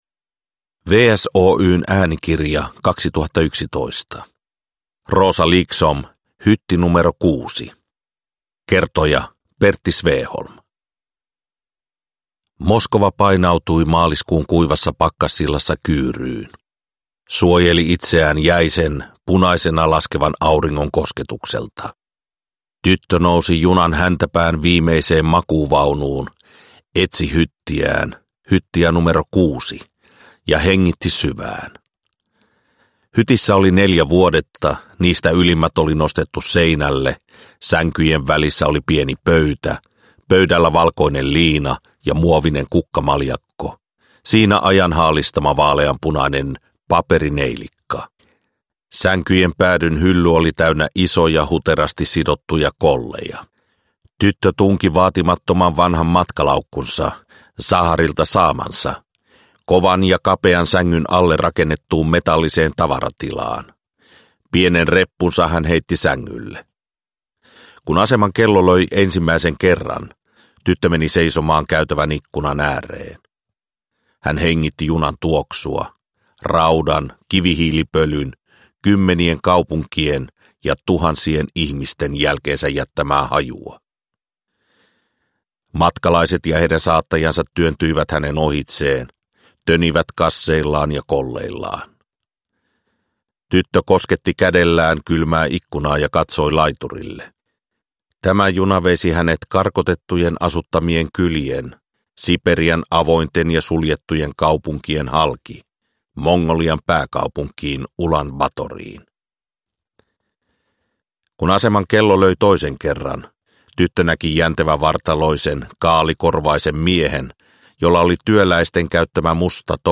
Hytti nro 6 – Ljudbok – Laddas ner